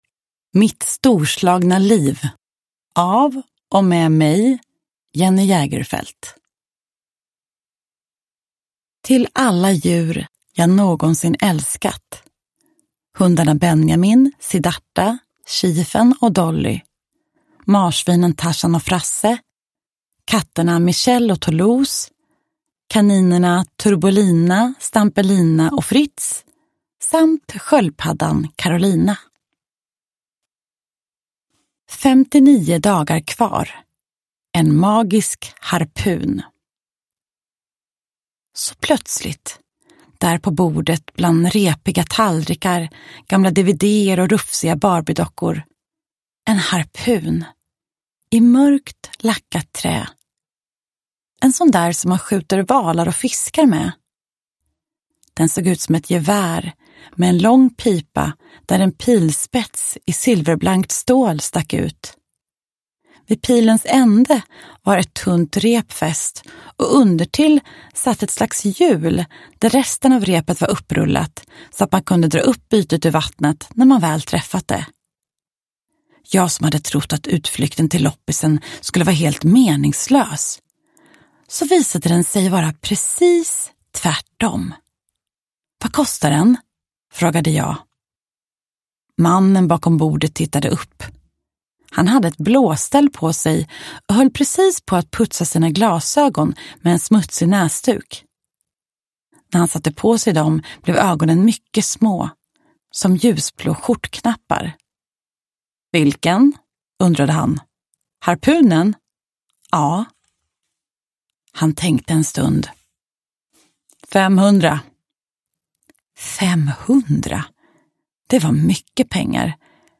Mitt storslagna liv – Ljudbok – Laddas ner
Uppläsare: Jenny Jägerfeld